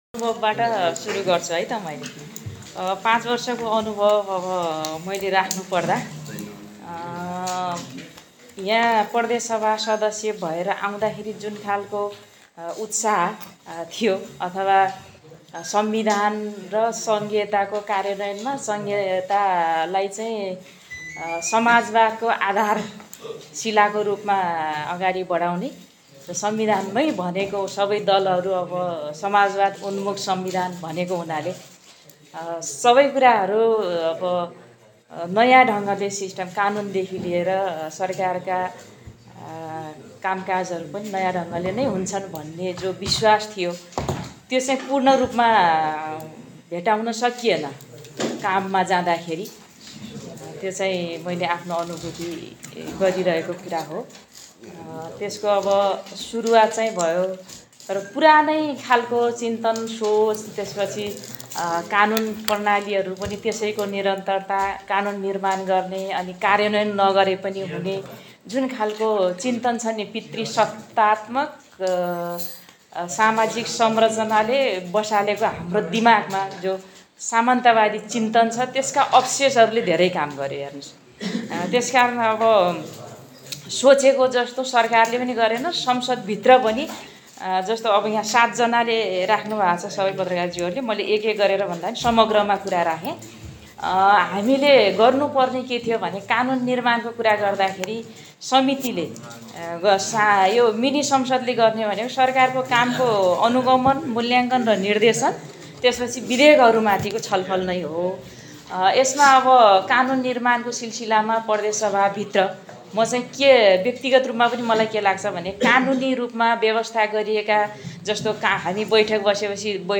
सुर्खेत । कर्णाली प्रदेश सभाको ‘मिनि पार्लियामेन्ट’को रुपमा पाँच वर्षे अवधीमा सबैभन्दा बढि सक्रिय रहेको सामाजिक विकास समितिले पाँच वर्षको कार्यकालमा ७० प्रतिशत सफलता प्राप्त गरेको दाबी गरेको छ । आज समितिको पदावधीको अन्तिम दिन समितिले आयोजना गरेको पत्रकार सम्मेलनलाई सम्वोधन गर्दै अध्यक्ष देवि ओलीले यस्तो दाबी गरेकी हुन ।